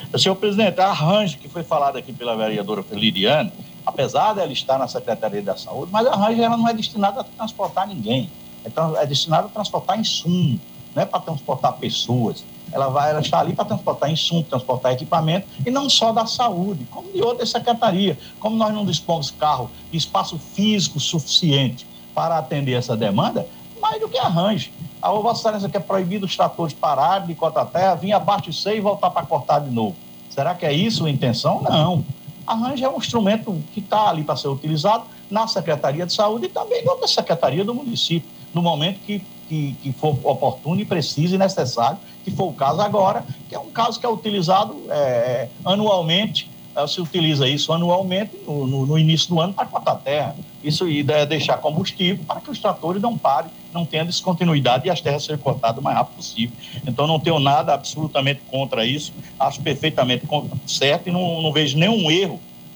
Devido à pandemia da Covid-19, a reunião foi realizada de maneira remota com transmissão da Rádio Conexão (104,9 FM).
O vereador Salomão Cordeiro (Republicanos) comunicou que apesar do veículo está na Secretaria de Saúde, o transporte serve a todas as secretarias, ouça: